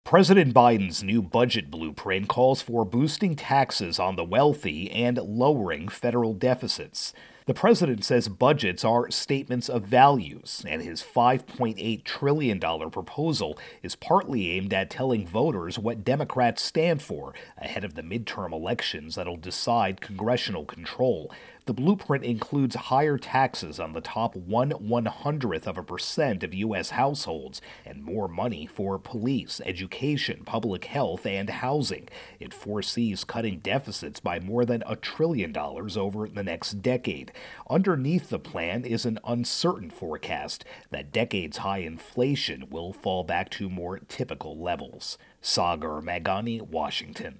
Biden-Budget intro and voicer.